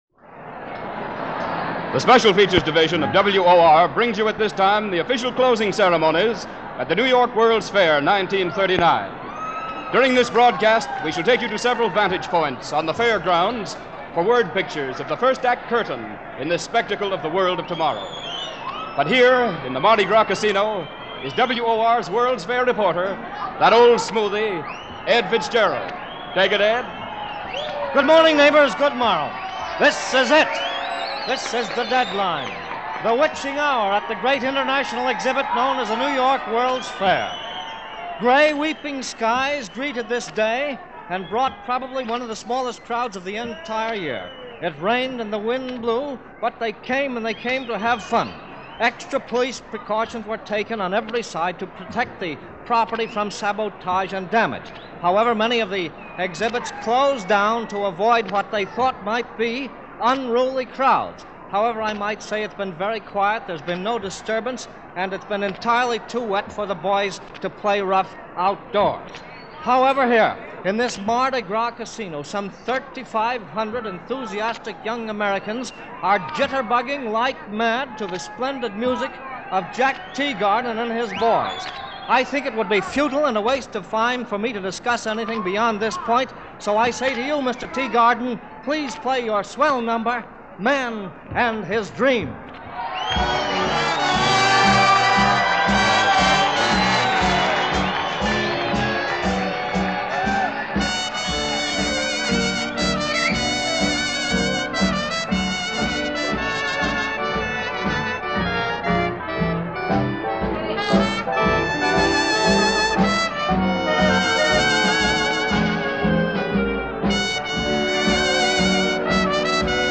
October 30, 1939 - Closing the New York World's Fair (for the winter) - Closing ceremonies in this live broadcast from the Fairground.